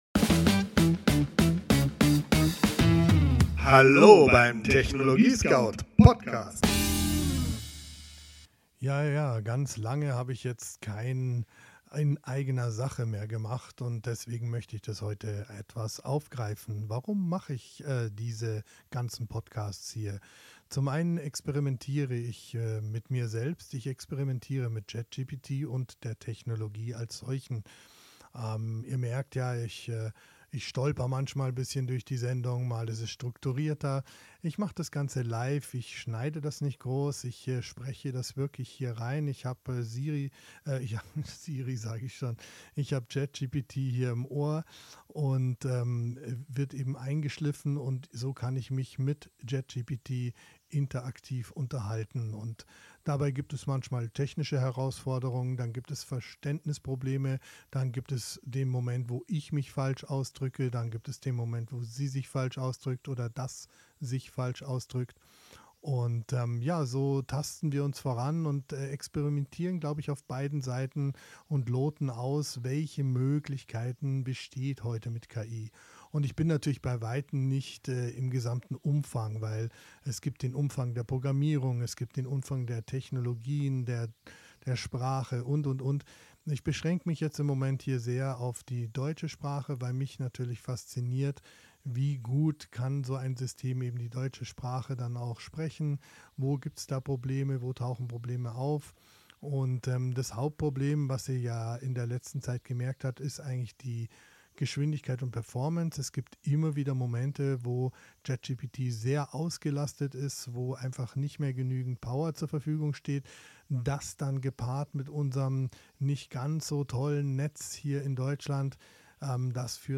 Dialog im TechnologieScout-Studio: